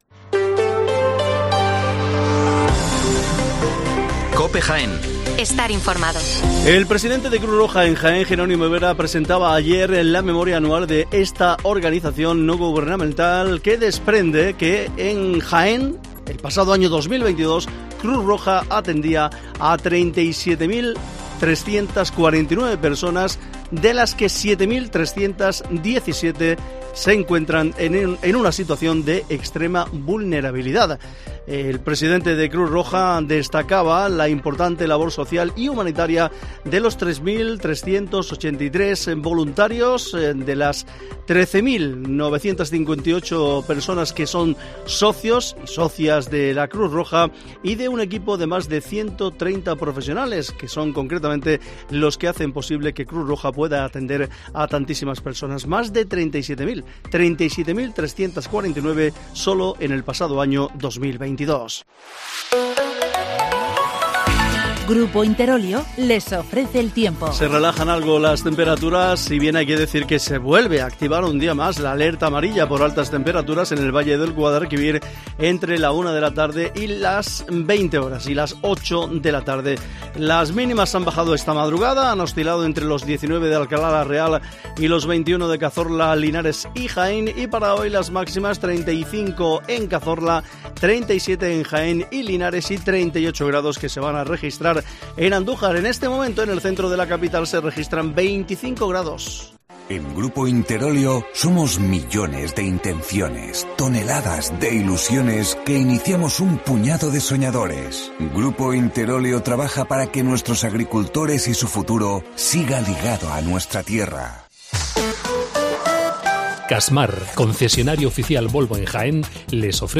Las noticias matinales en Herrera en COPE 8:24 horas